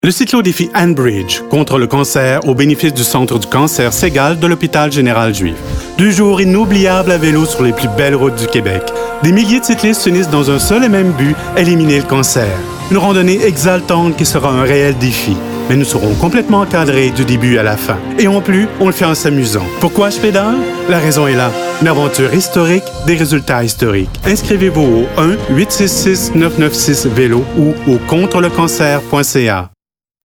Man
For Promos and Presentations: Velvet-toned, smooth and professional.
kanadisch-fr
Sprechprobe: Industrie (Muttersprache):